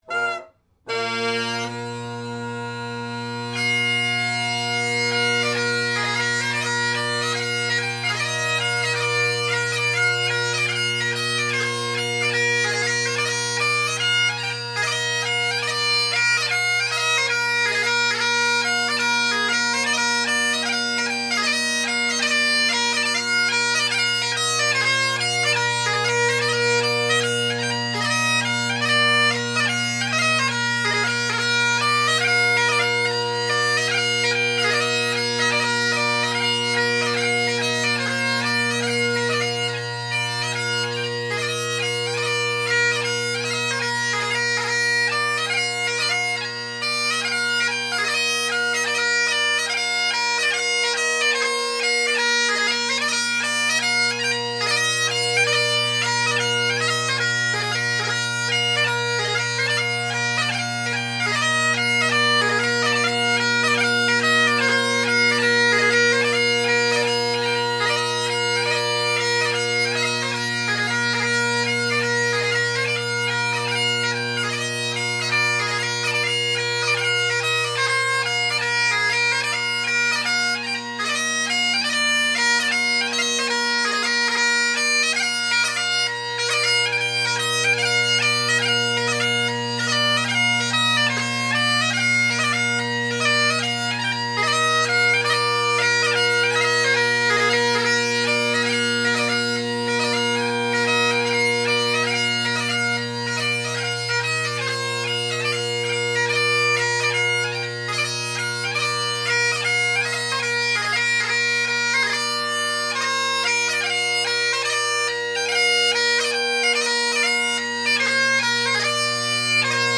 Great Highland Bagpipe Solo
Highly indicative of this is the instability of low A. Oddly enough, if you blow harder on low A it gets flatter, rather than sharper.
So, a little instability in the chanter but here they are anyway. Marches!